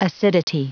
Prononciation du mot acidity en anglais (fichier audio)
Prononciation du mot : acidity